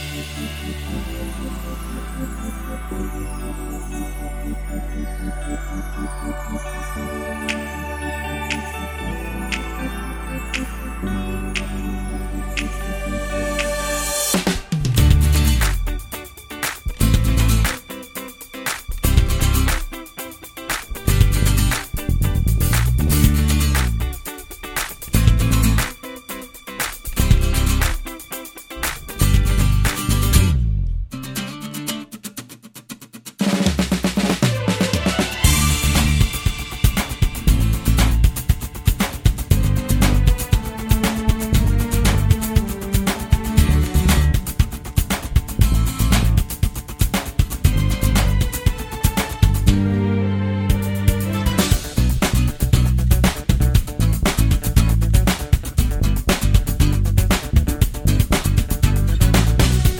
no Backing Vocals Musicals 3:46 Buy £1.50